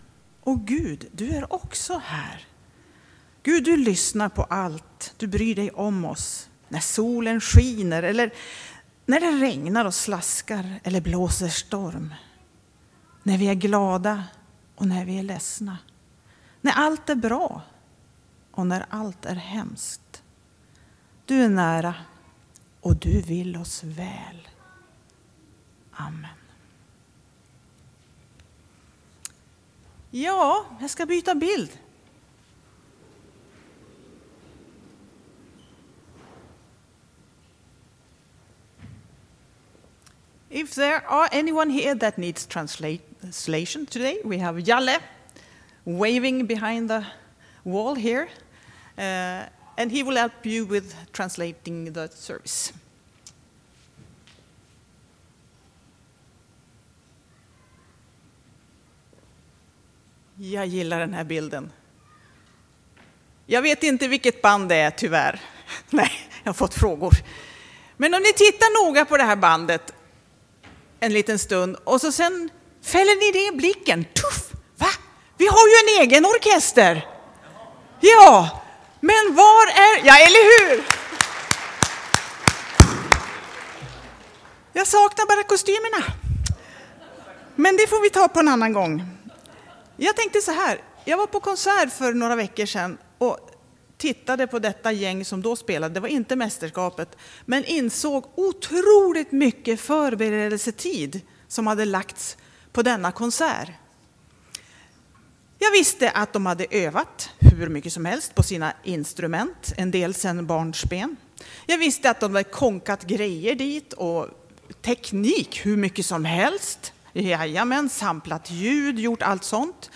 Söndagens välbesökta iCentrum behandlade ämnet prövningar på ett vardagsnära och fint sätt.